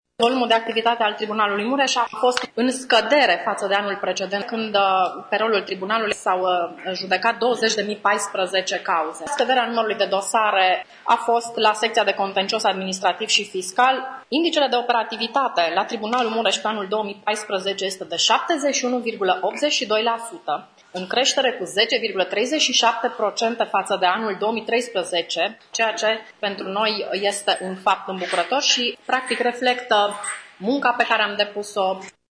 Este una dintre concluziile formulate de președintele Tribunalului Mureș, judecătorul Ioana Lucaci la bilanțul instituției pe anul 2014.
Judecătorul Ioana Lucaci a remarcat atât scăderea încărcăturii de dosare pe judecător, a stocului de dosare la jumătate, respectiv creșterea operativității Tribunalului Mureș: